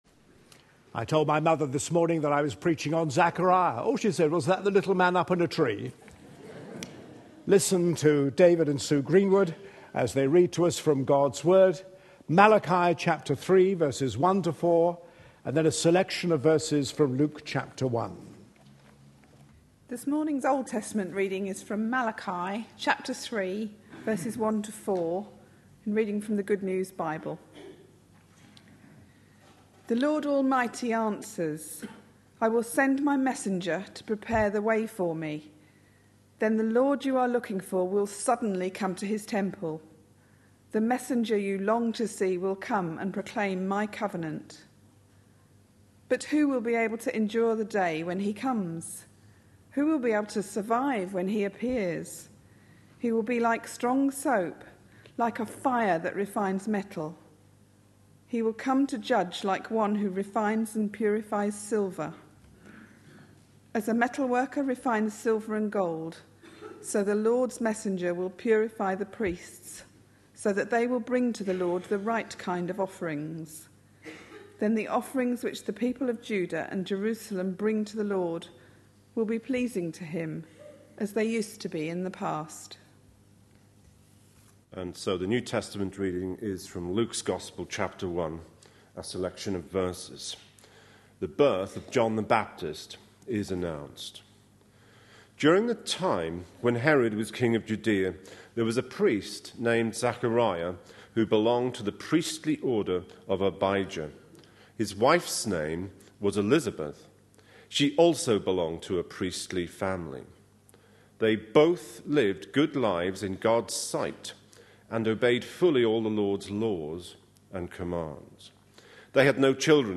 A sermon preached on 9th December, 2012.